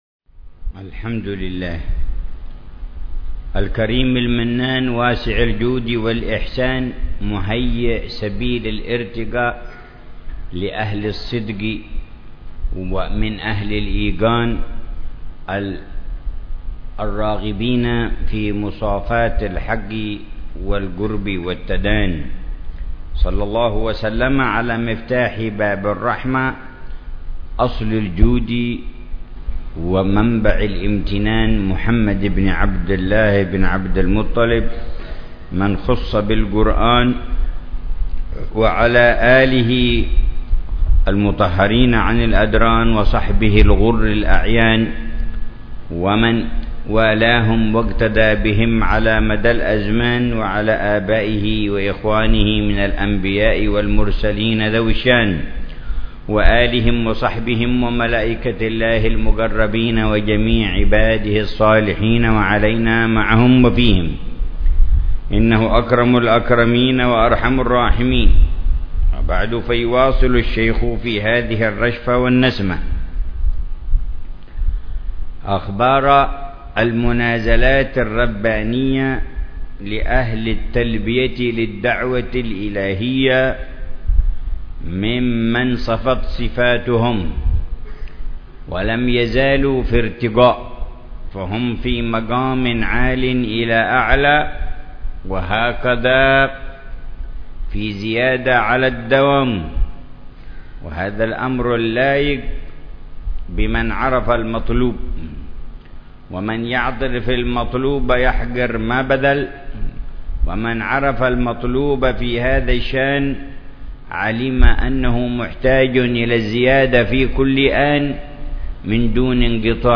رشفات أهل الكمال ونسمات أهل الوصال - الدرس الثامن والأربعون
شرح الحبيب عمر بن محمد بن حفيظ لرشفات أهل الكمال ونسمات أهل الوصال.